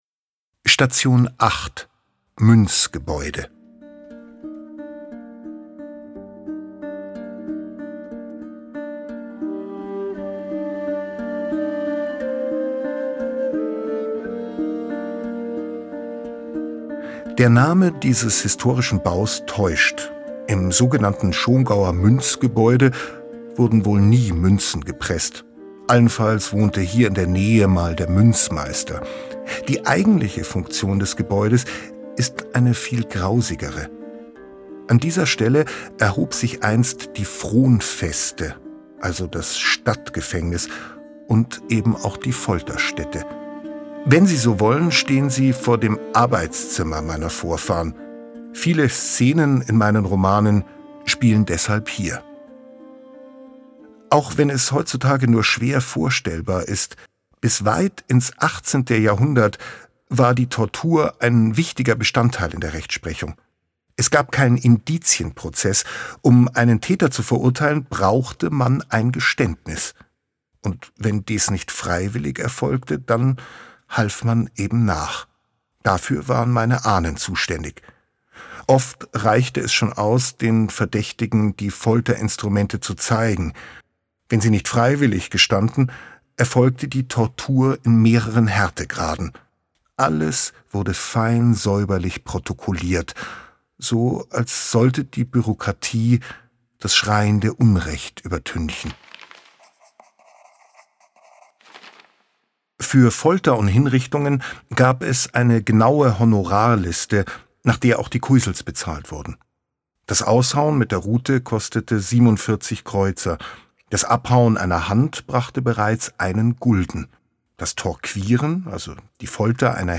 Erleben Sie einen erlebnisreichen und spannenden Rundgang durch die historische Altstadt von Schongau mit Audiokommentaren von Bestsellerautor Oliver Pötzsch!
Audiokommentar zur Station 8, Münzgebäude
Audioguide_Schongau-08-Muenzgebaeude.mp3